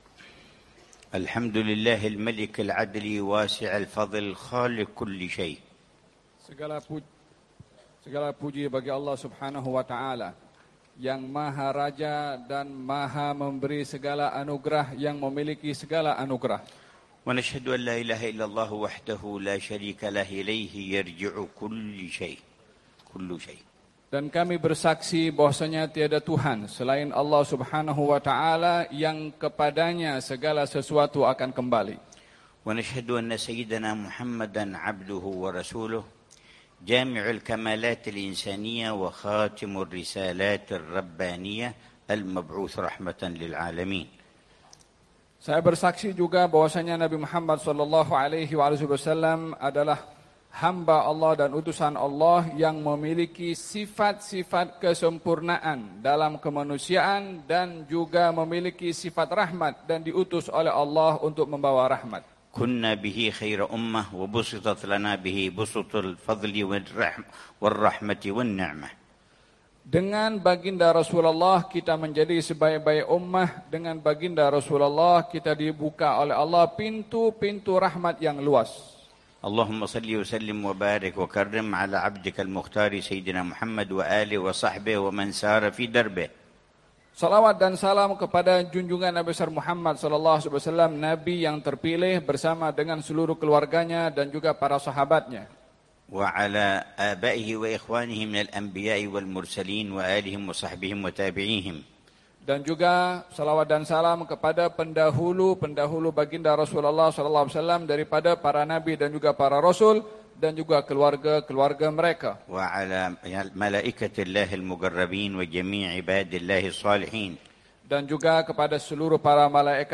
محاضرة للعلامة الحبيب عمر بن حفيظ، في الملتقى القومي للدعاة، في بجامعة سلطان زين العابدين في ولاية ترنغانو، ماليزيا، الثلاثاء 22 ربيع الثاني 1447هـ بعنوان: